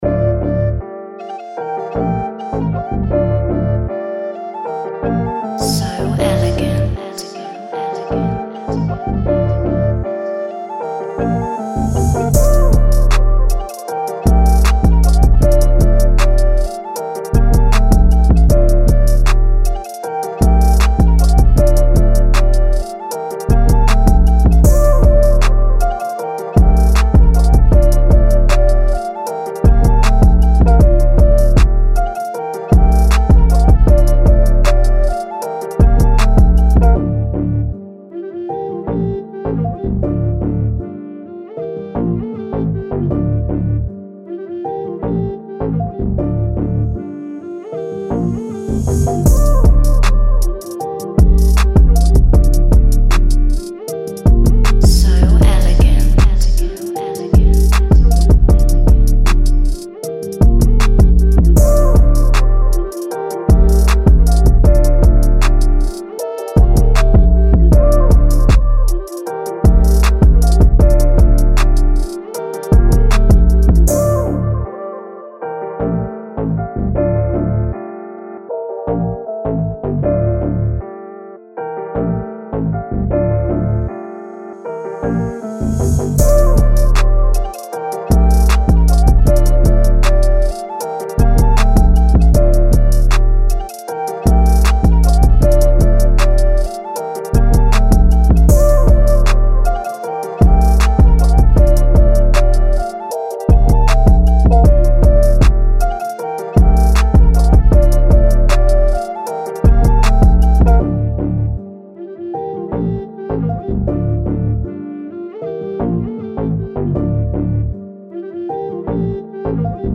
Hip-Hop , Trap